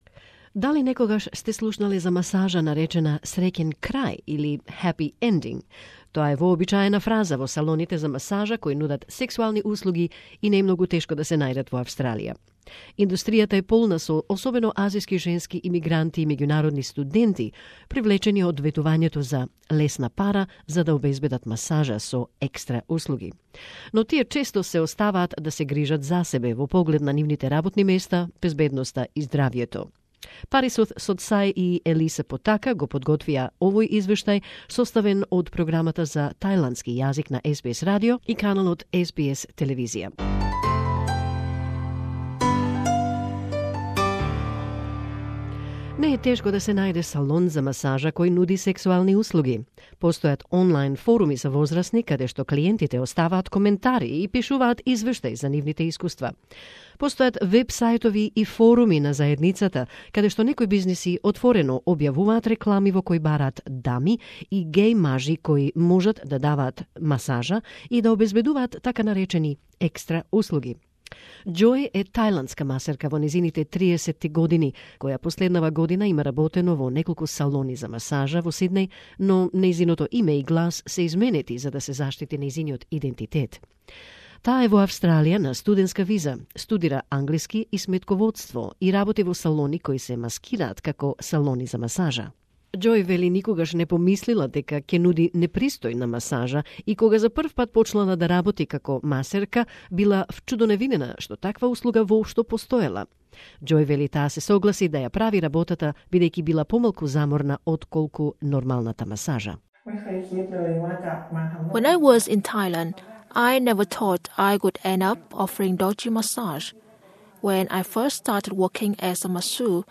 How much cash do mainly young Asian students and international migrants make by providing “extra” services in massage parlours. In a confidential interview for SBS Thai, one masseuse says that kind of massage is less tiring than the full body massage.